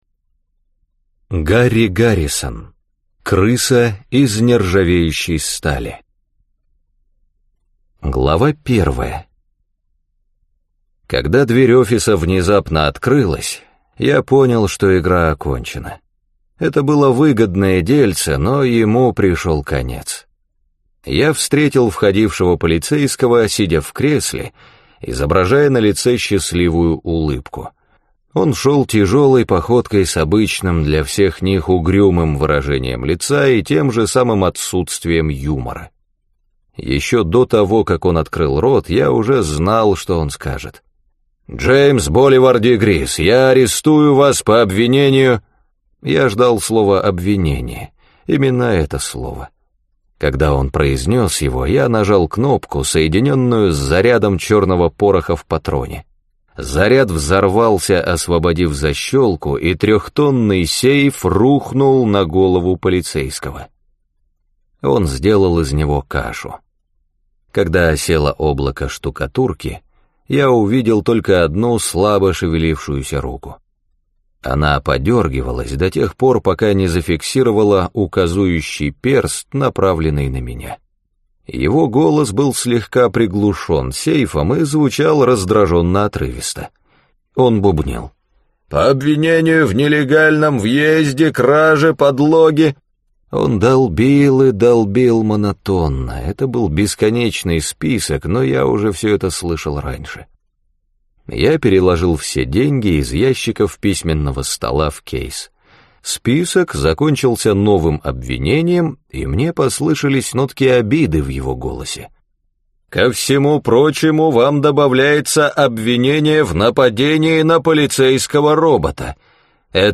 Аудиокнига Крыса из нержавеющей стали | Библиотека аудиокниг